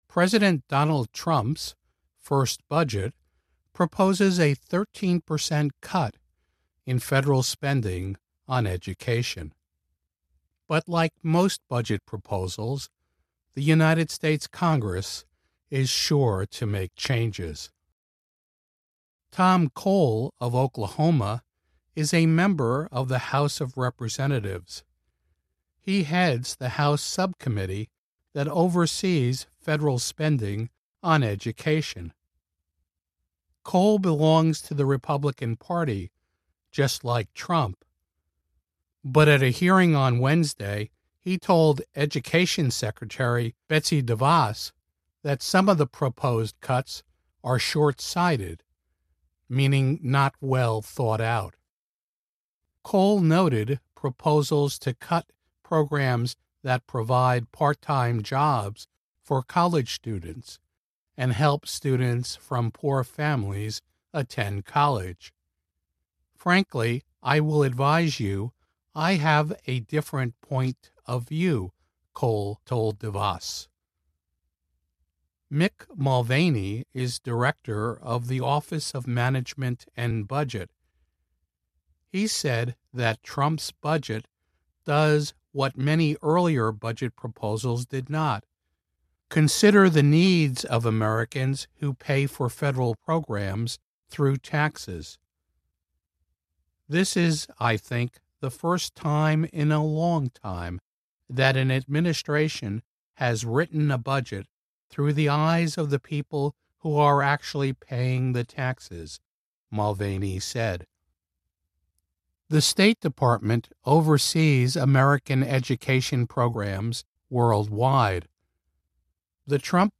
VOA Special English, Education Report, US Congress Reviews Plan to Cut Education Budget